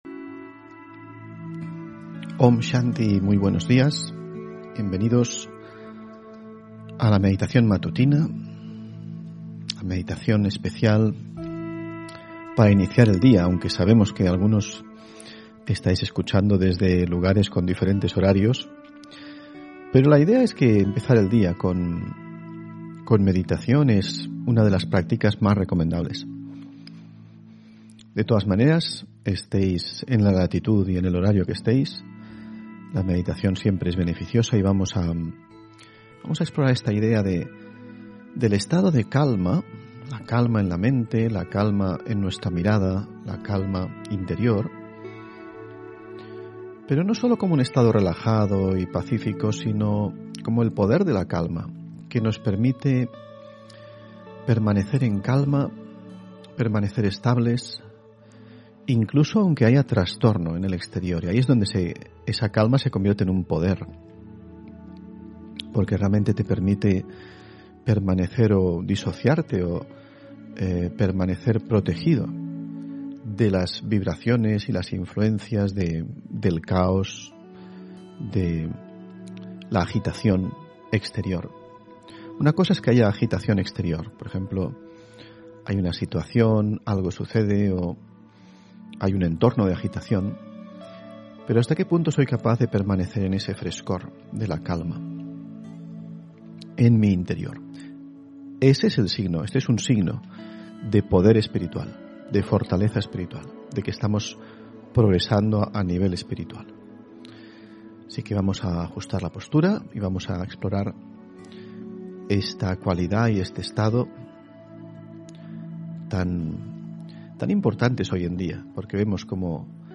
Meditación y conferencia: Leyes espirituales para una vida serena (6 Octubre 2022)